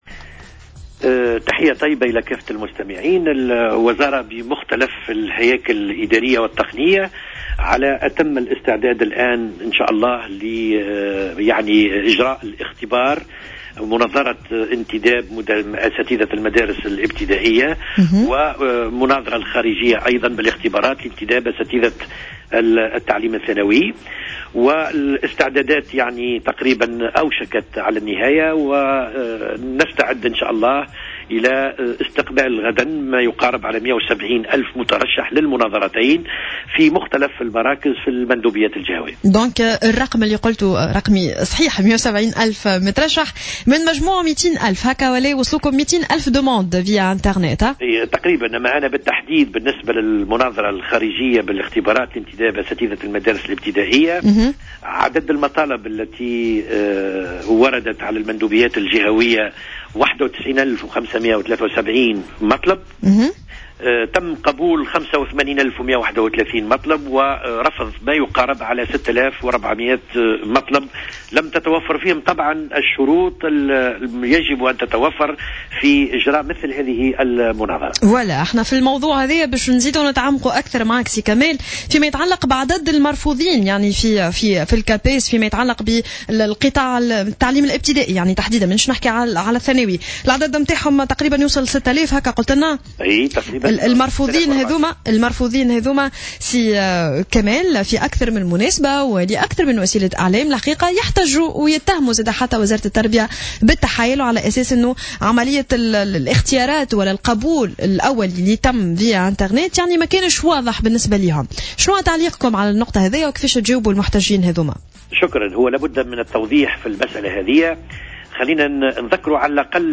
أكد كمال الحجام المدير العام للمرحلة الابتدائية في مداخلة له صباح اليوم السبت على "جوهرة أف أم" أن حوالي 170 ألف مترشح سيجتازون غدا الأحد 10 ماي 2015 المناظرة الخارجية لانتداب الأساتذة والمعلمين من مجموع 200 ألف.